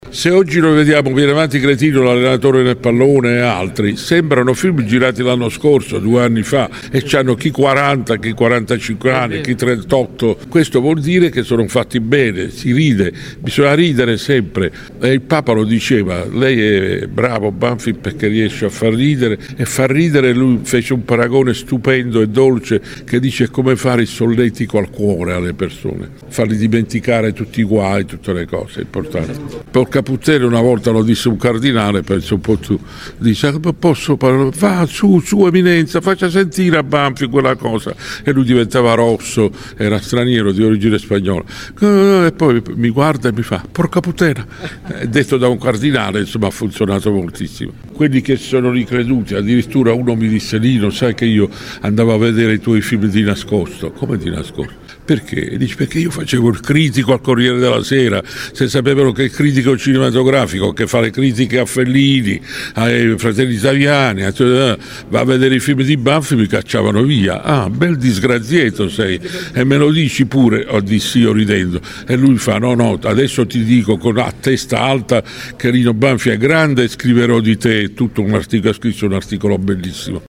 Un incontro carico di emozione e simpatia quello che si è tenuto oggi al foyer del Teatro D’Annunzio di Latina, dove Lino Banfi ha presentato in anteprima lo spettacolo che andrà in scena il 27 novembre, inserito nel cartellone della stagione teatrale 2025-2026 del teatro comunale.